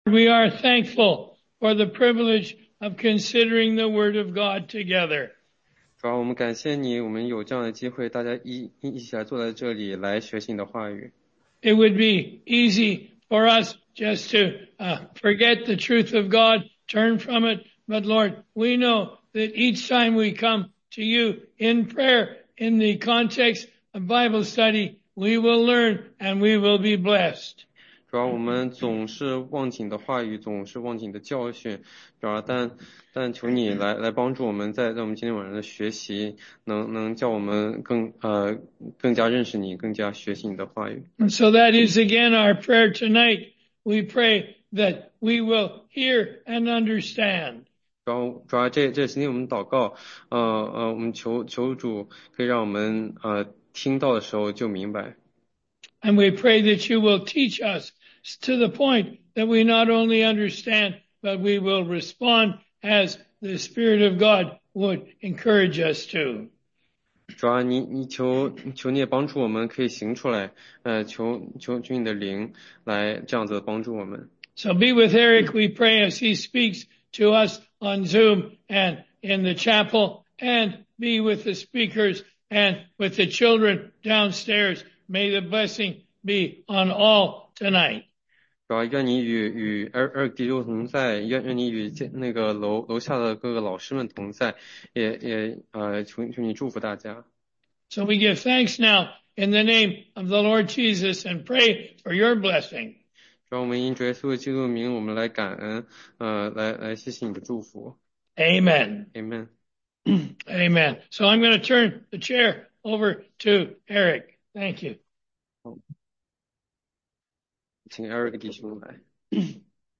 16街讲道录音 - 约翰福音解读—耶稣用铜蛇的故事解释祂为什么要上十字架（3章10-15节）
中英文查经